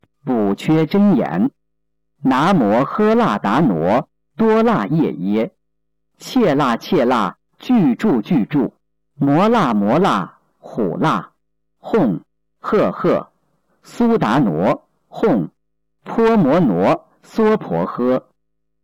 016《补阙真言》教念男声